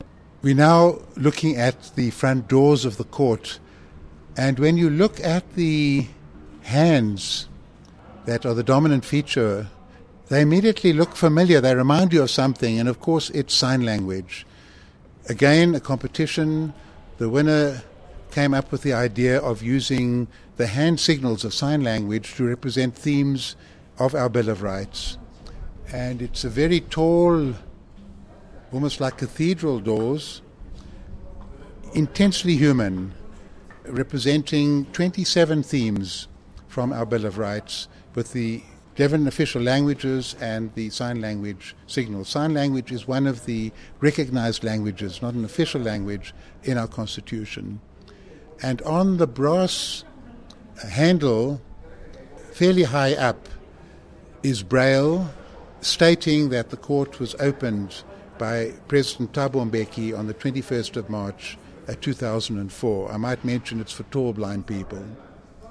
Albie Sachs speaking to the wooden entrance doors (audio)